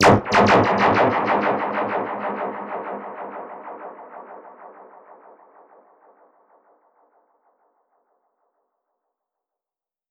DPFX_PercHit_C_95-08.wav